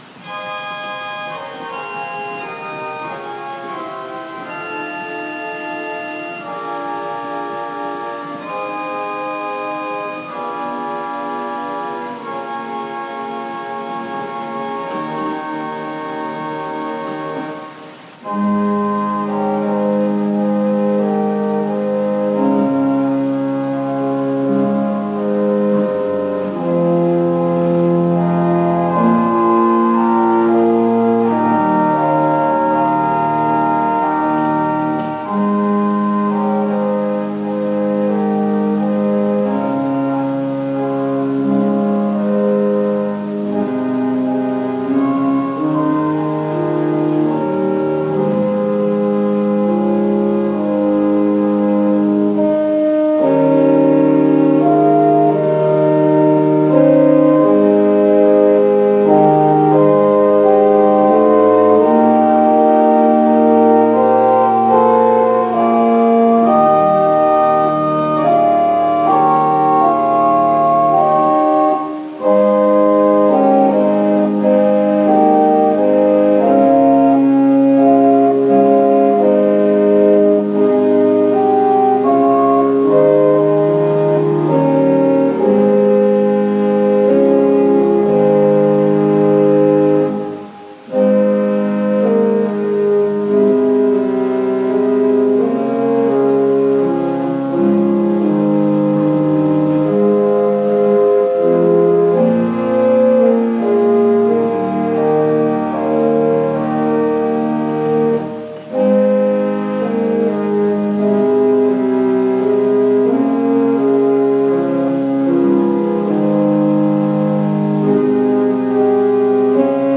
Worship Service September 27, 2020 | First Baptist Church, Malden, Massachusetts